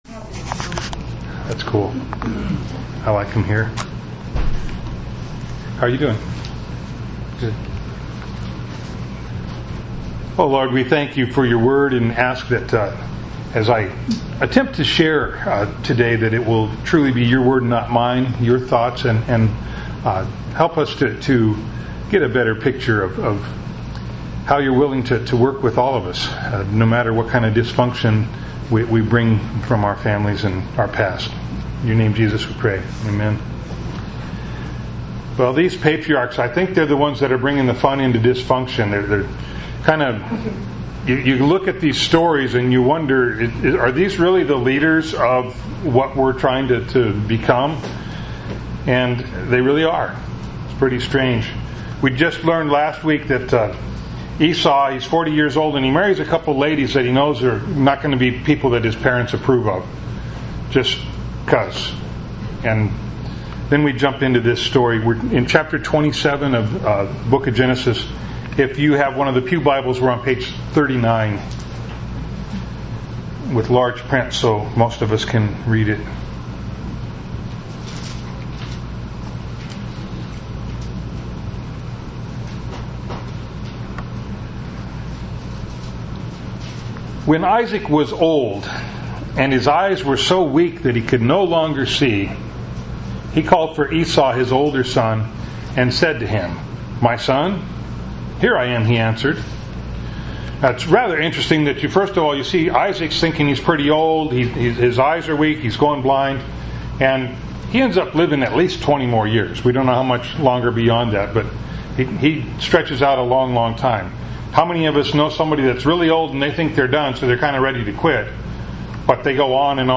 Bible Text: Genesis 27:1-45 | Preacher